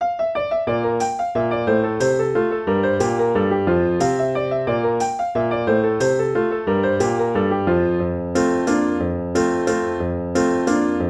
Partitura para piano, voz y guitarra.